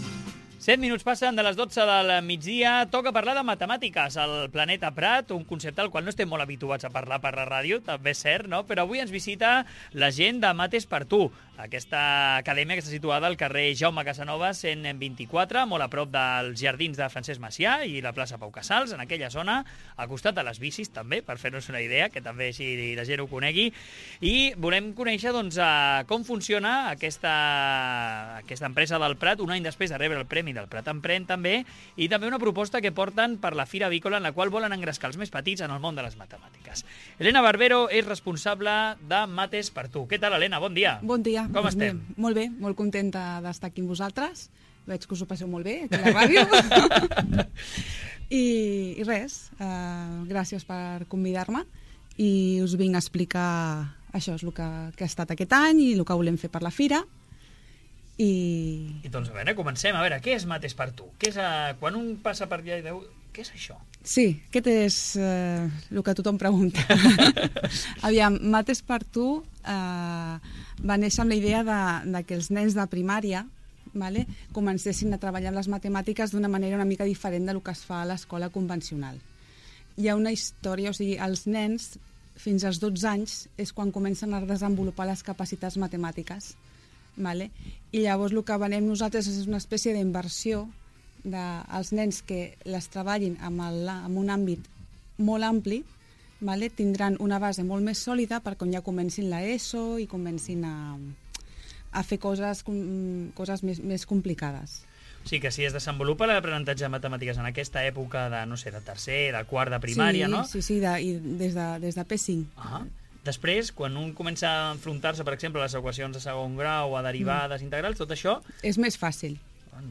HEMOS ESTADO EN LA RADIO DEL PRAT - MATESxTU educación